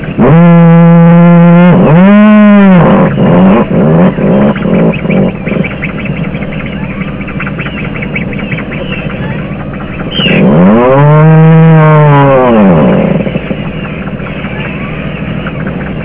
Puffin Talk
They call out with their peep-peep-peep, telling their parents they're hungry.
Puffins talk in their underground burrows.
So the sound one hears them make in their soft earthen rooms is a soft growling-moaning.
Their voice reverberates off these hard rock walls so the sound one hears is more like a chain saw.